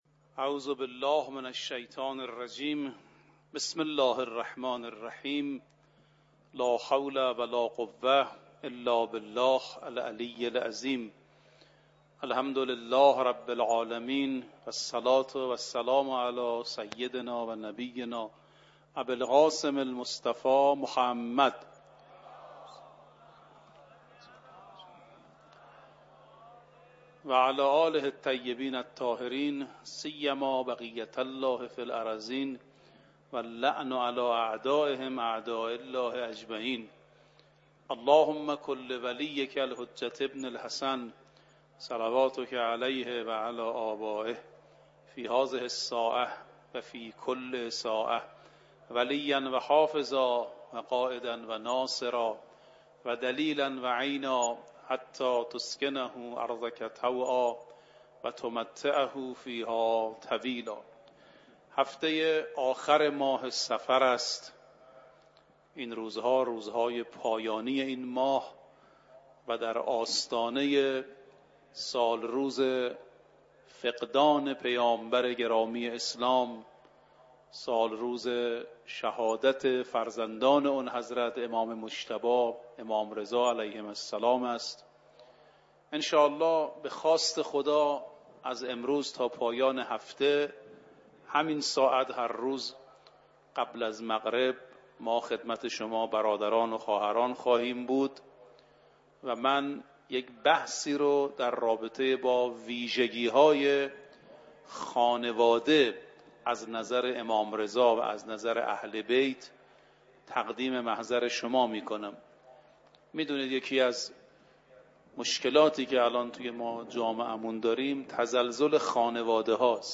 مدت سخنرانی : 25 دقیقه